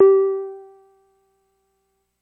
标签： MIDI网速度48 FSharp4 MIDI音符-67 Arturia-Microbrute 合成器 单票据 多重采样
声道立体声